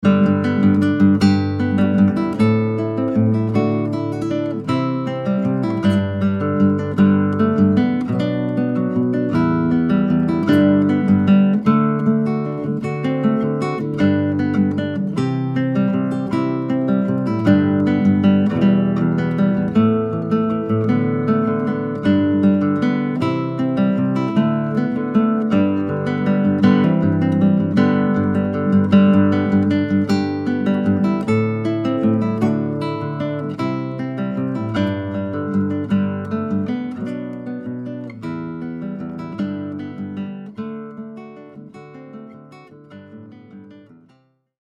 Audio Examples (solo guitar)
Popular (arrangements of rock and popular songs)